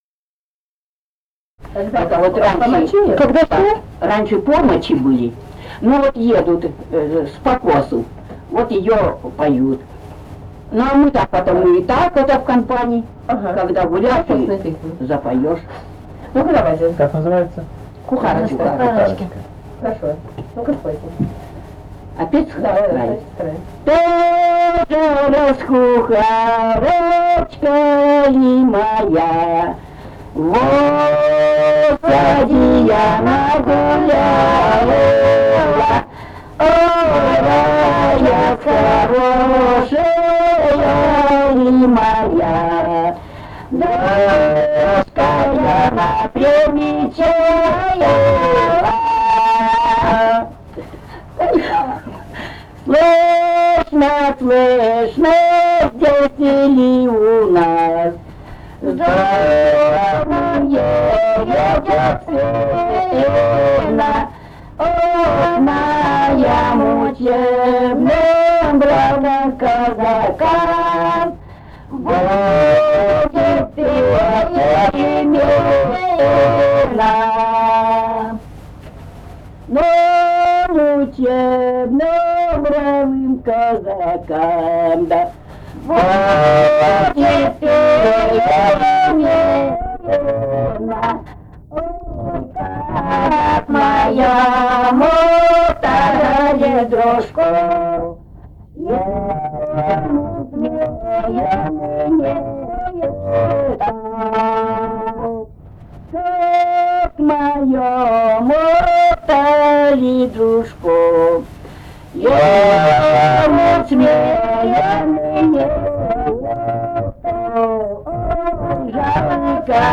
Алтайский край, с. Тигирек Краснощёковского района, 1967 г. И1019-04